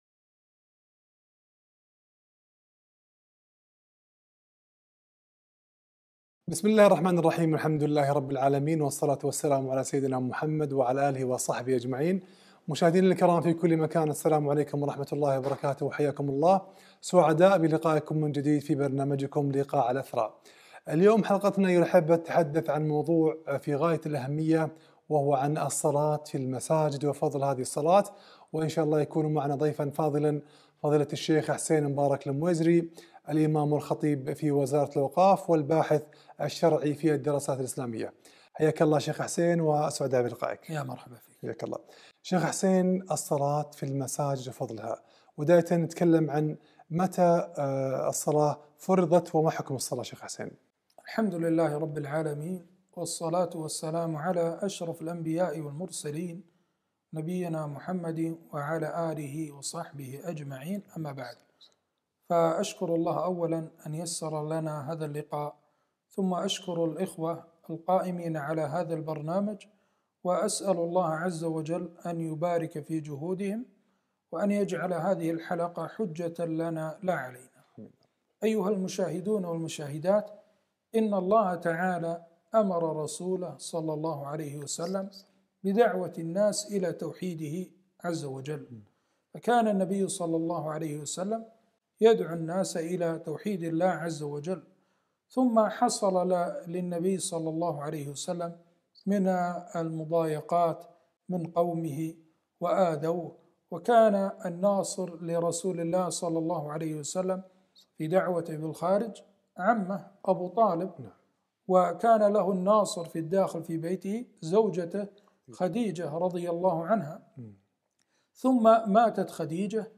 فضل الصلاة في المساجد - لقاء على قناة إثراء